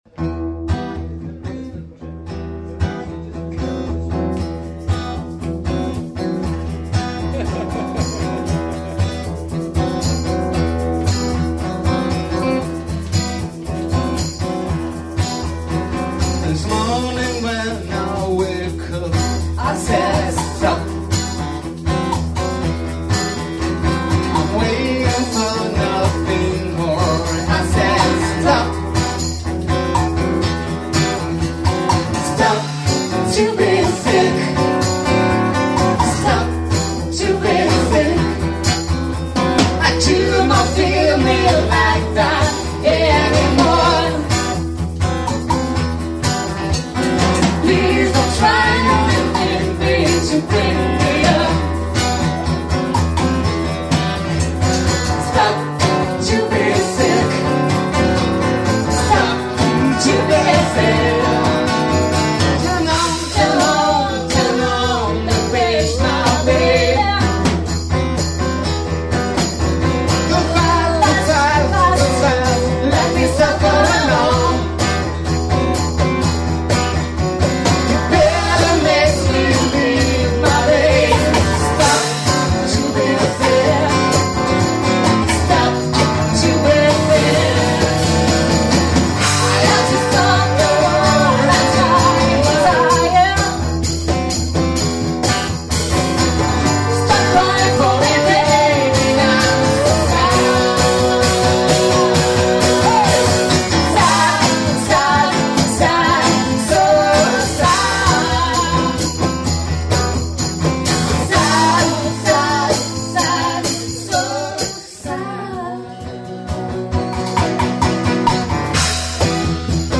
Parfois , on a le blues .......